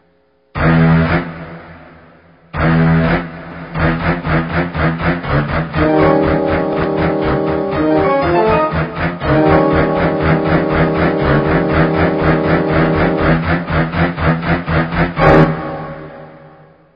Category: Scary Ringtones